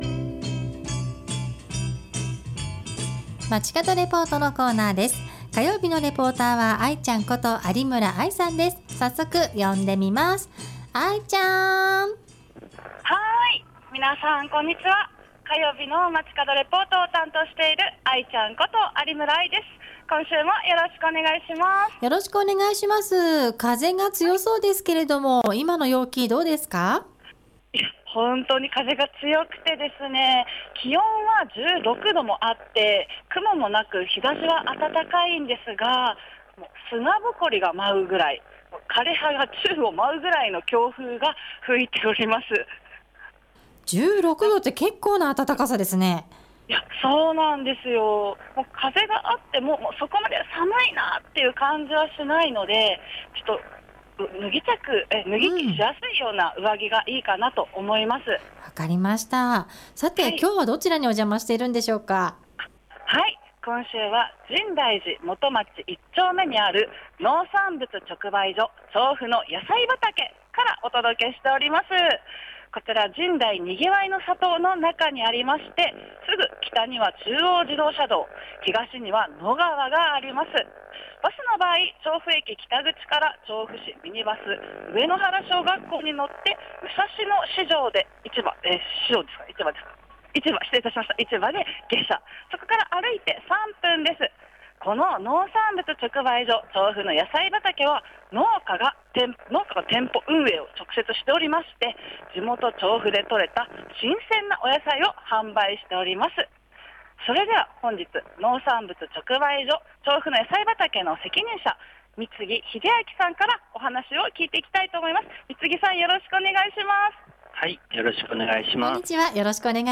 今週は「農産物直売所 調布のやさい畑」からお届けしました！